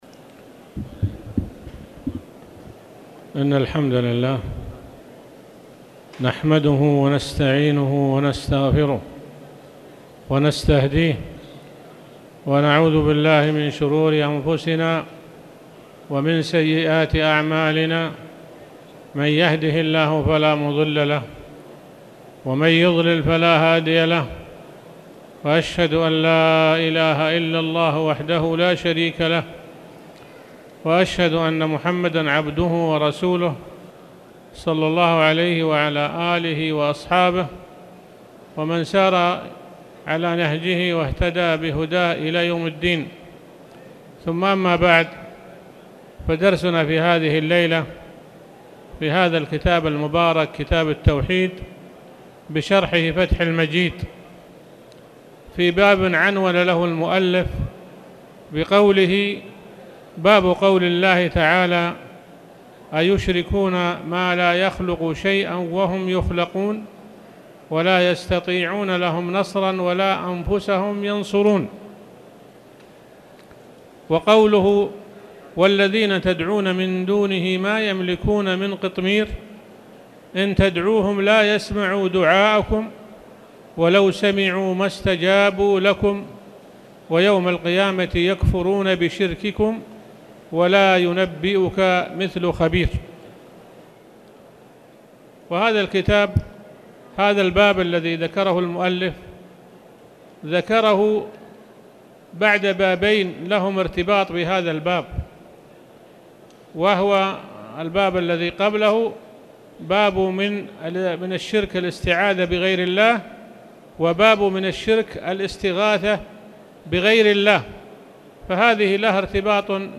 المكان: المسجد الحرام